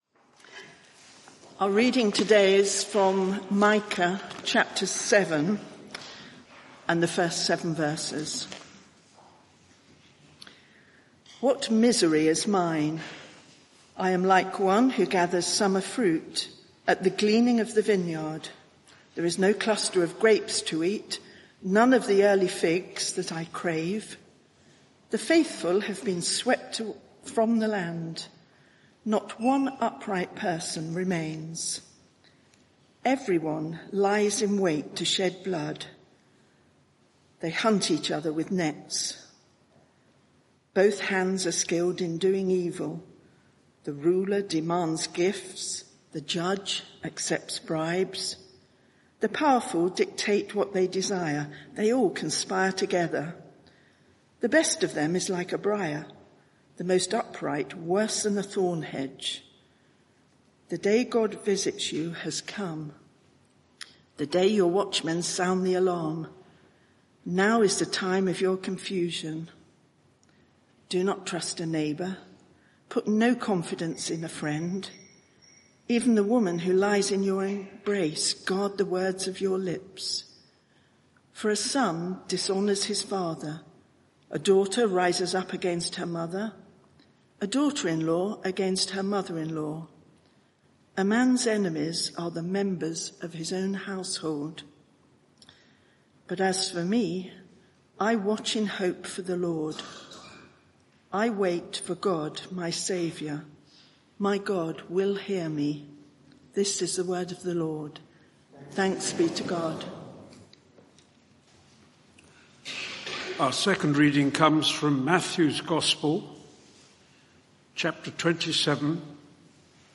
Media for 11am Service on Sun 13th Jul 2025 11:00 Speaker
Passage: Micah 7:1-7 Series: Who is like the Lord our God? Theme: Sorrow Expressed There is private media available for this event, please log in. Sermon (audio) Search the media library There are recordings here going back several years.